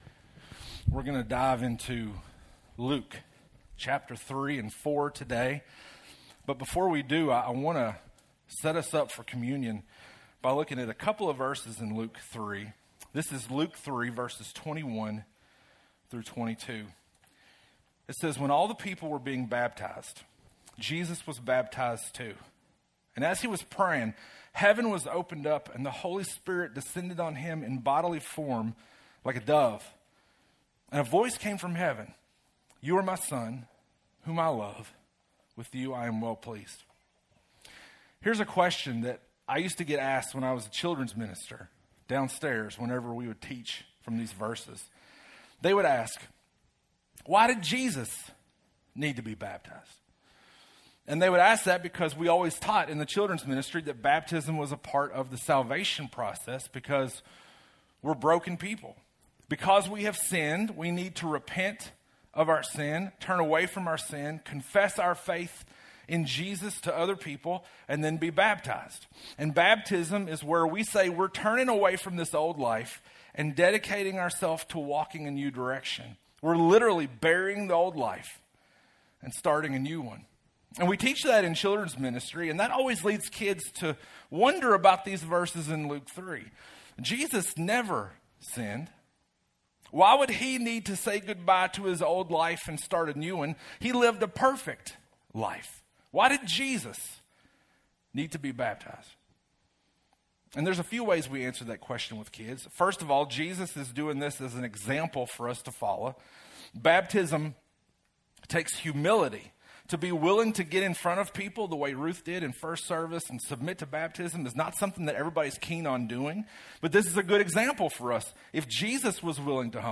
6.29_sermon-1.mp3